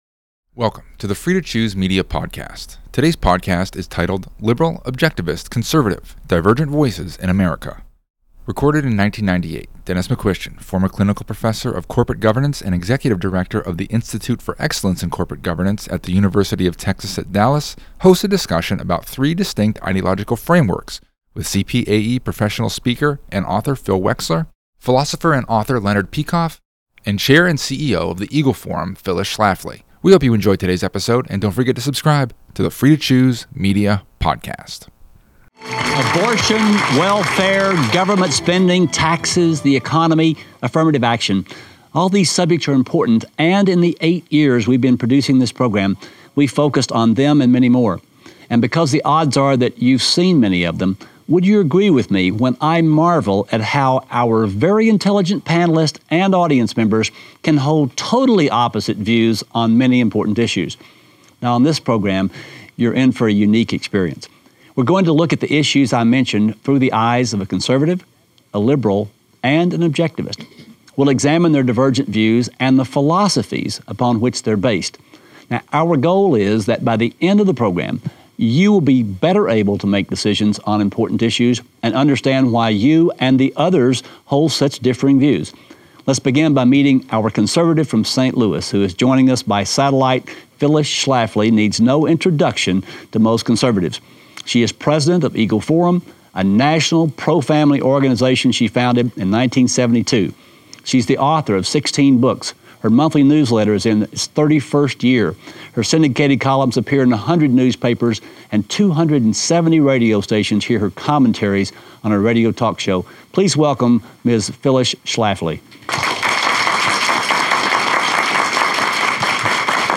hosts a discussion about three distinct ideological frameworks